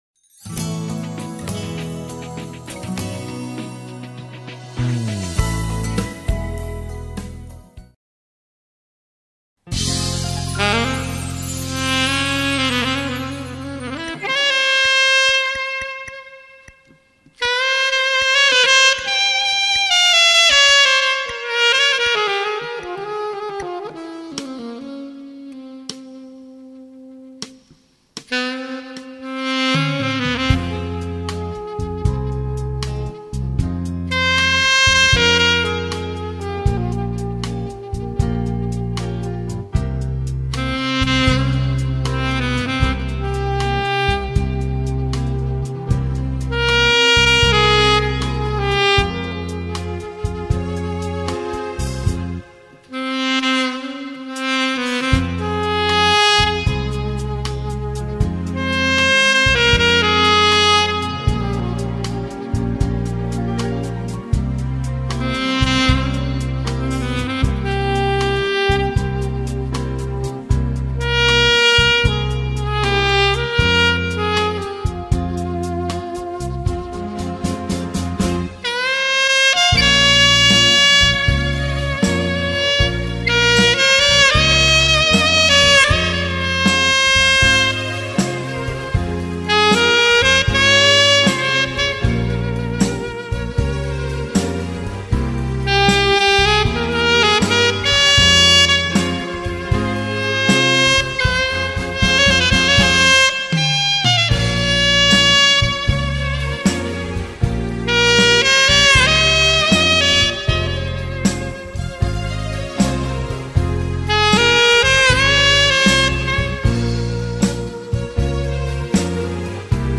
Только маленький нюанс...а нельзя ли вырезать в начале заставку конкурса.....извините за беспокойство, чтоб  звучала одна только мелодия.